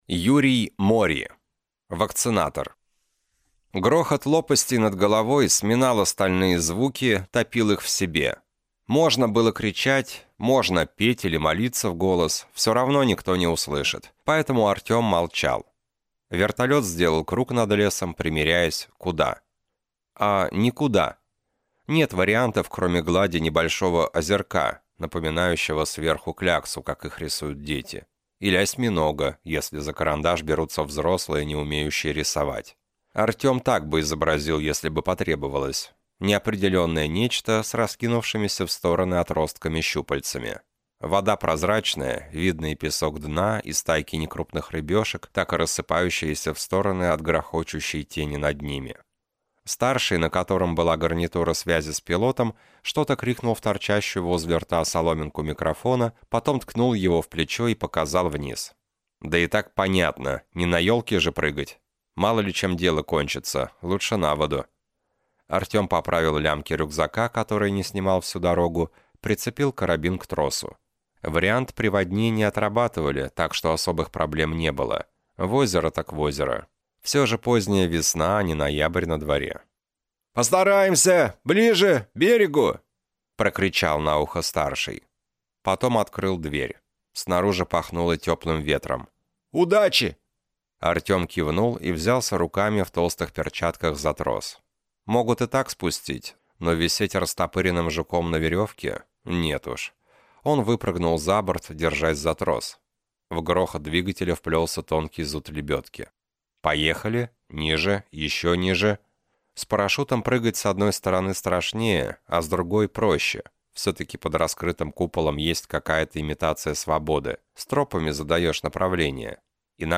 Аудиокнига Вакцинатор | Библиотека аудиокниг
Прослушать и бесплатно скачать фрагмент аудиокниги